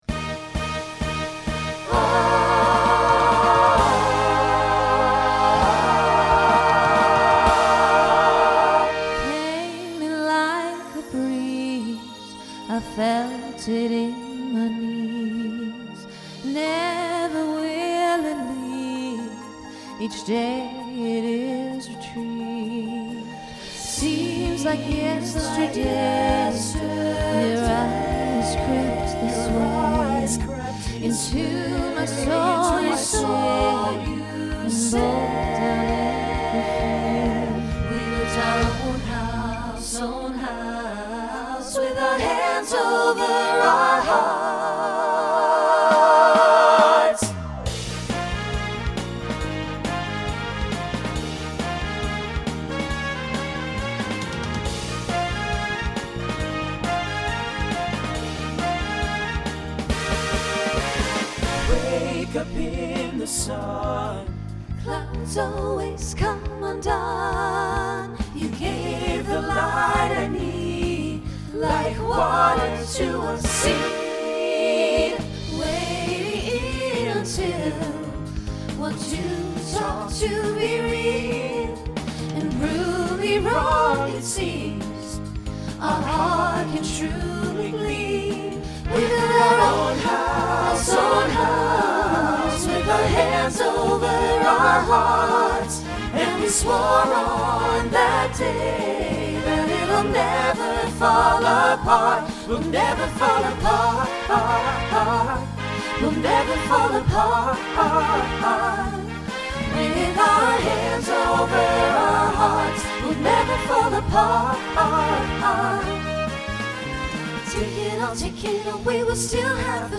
SATB Instrumental combo
Pop/Dance